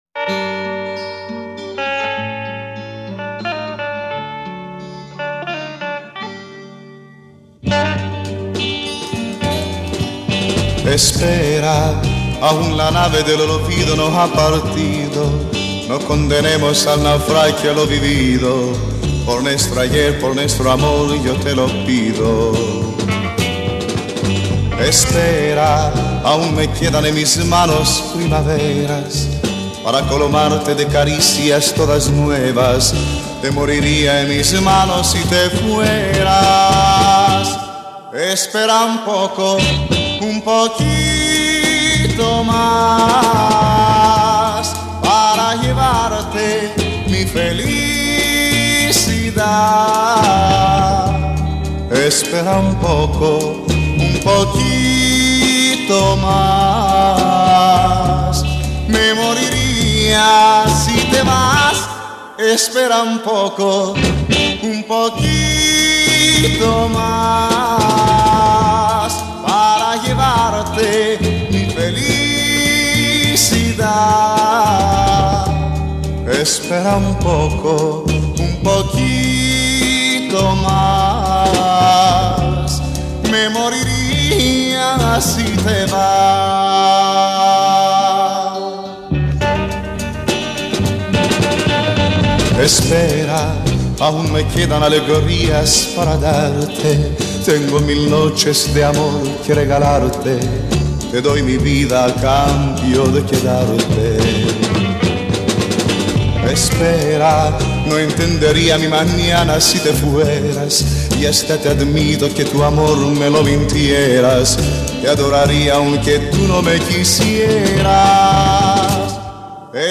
с шопеновской прелюдией